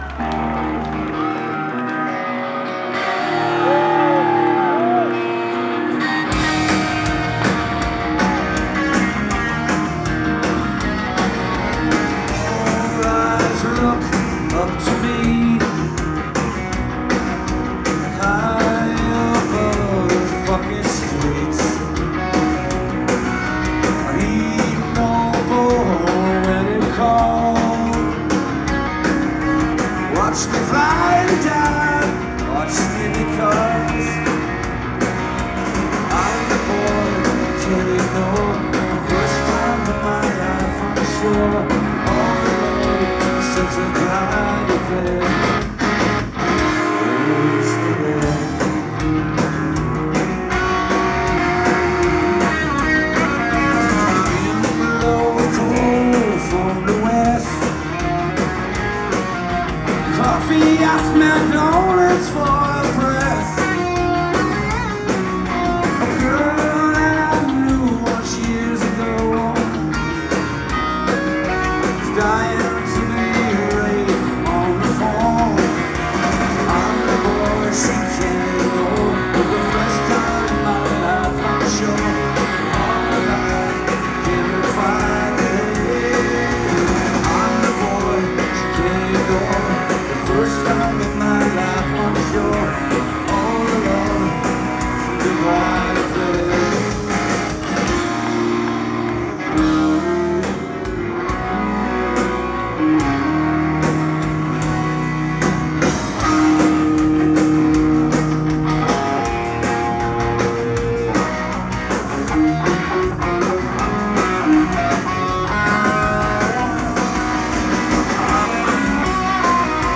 Live at the Sinclair, Cambridge, MA